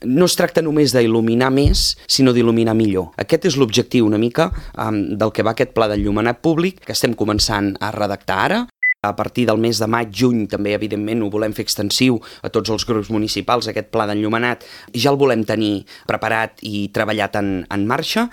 Així ho ha anunciat el tinent d’alcaldia de Serveis públics, Josep Grima, a l’ENTREVISTA POLÍTICA de Ràdio Calella TV, l’espai setmanal amb els portaveus polítics municipals.